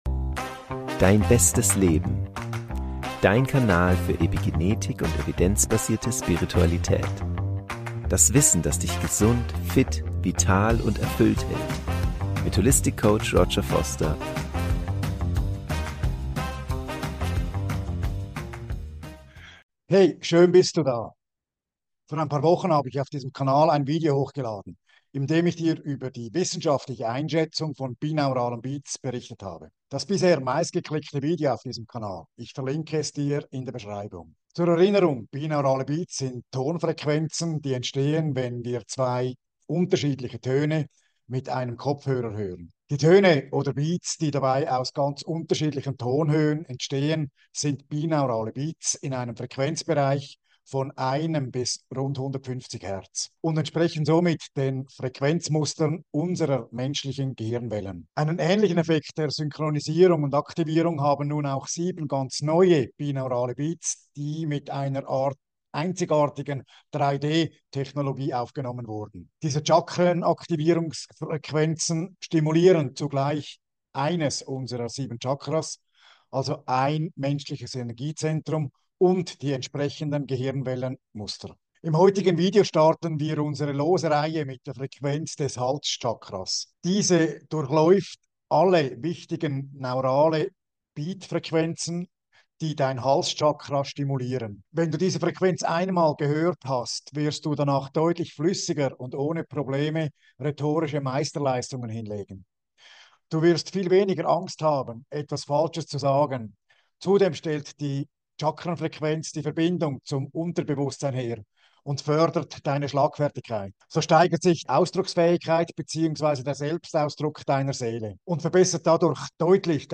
In diesem Video erhältst Du eine Meditation zur Aktivierung Deines Hals-Chakras und zur Stimulation der Hörbereiche und Hirnwellen, die mit Deinem Ausdruck zu tun haben. Die Frequenz der Töne ist auf das Chakra und Hirnwellen abgestimmt, sodass sie dadurch gereinigt und aktiviert werden.
Am besten hörst Du die Meditation mit Kopfhörern im Liegen oder Sitzen, um die Frequenz wahrnehmen und in einen tiefen Entspannungszustand gelangen zu können.